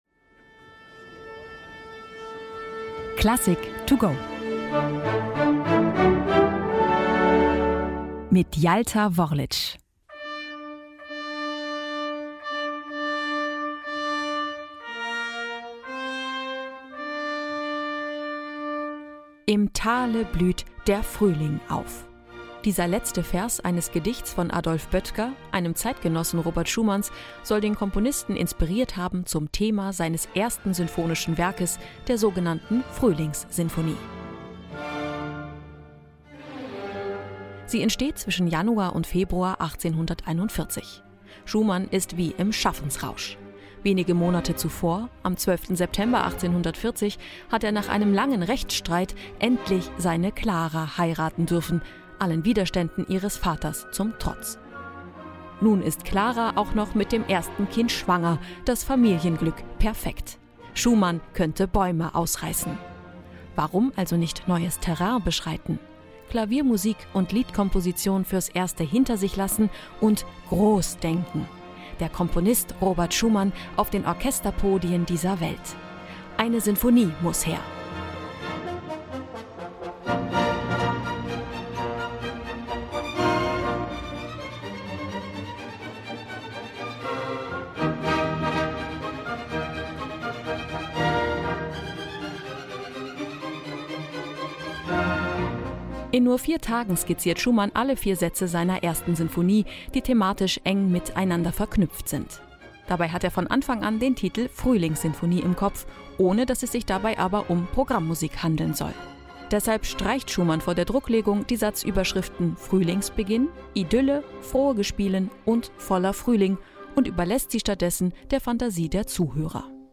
Mit der kurzen Werkeinführung für den Weg zum Konzert bestens